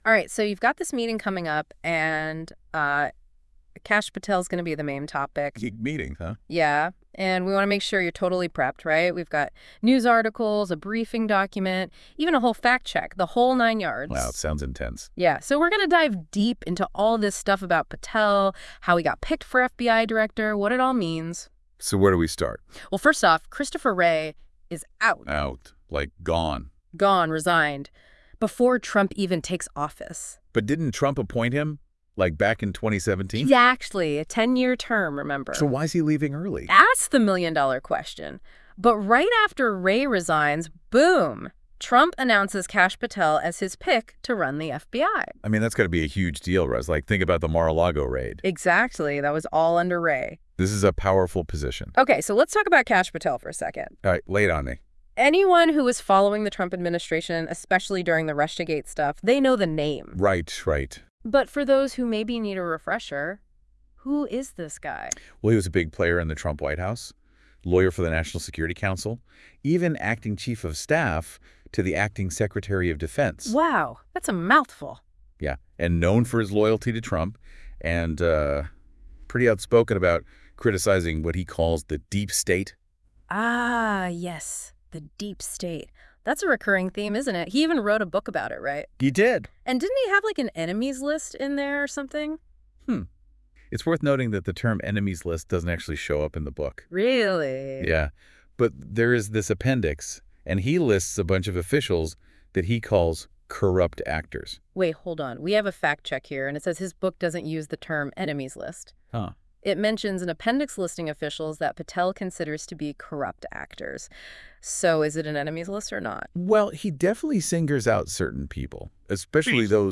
Listen to a convo on it all….